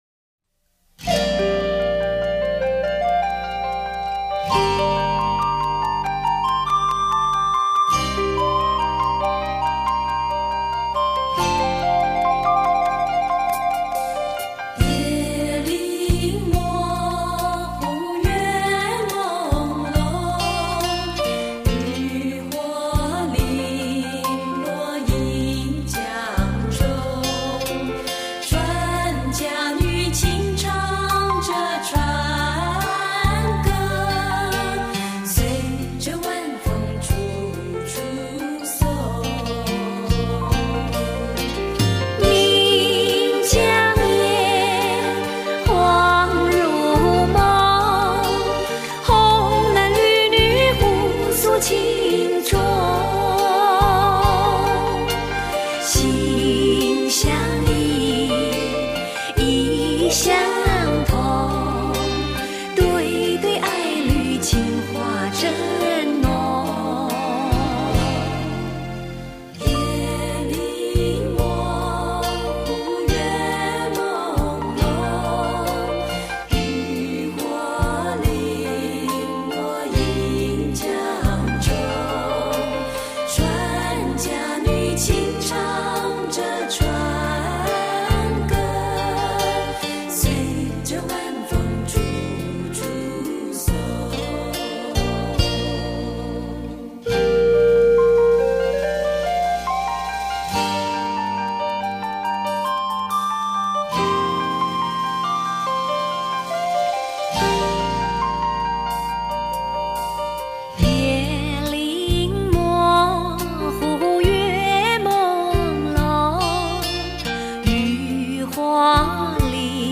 专辑中以女声小合唱形式演绎各个不同年代的经典老歌，别有一番韵味。
注：此碟部分曲子音轨有损伤，只是作为凑齐这一系列。见谅！